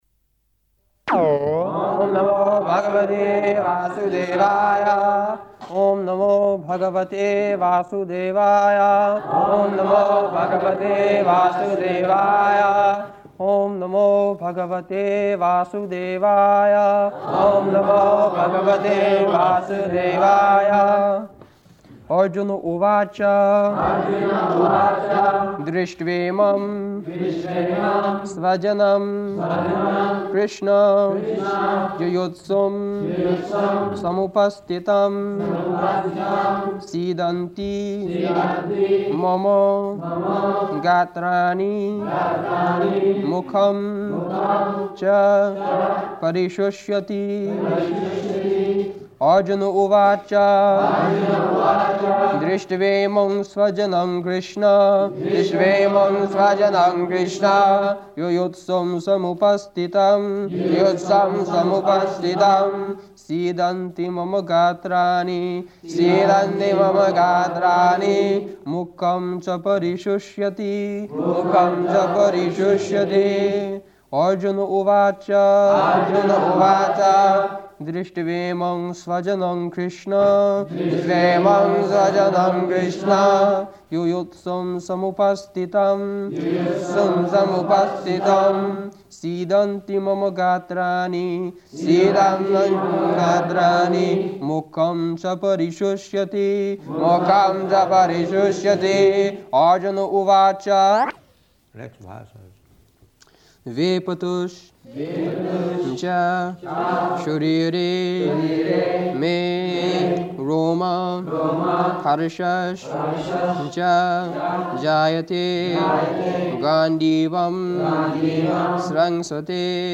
July 22nd 1973 Location: London Audio file
[devotees repeat] [leads chanting of verse]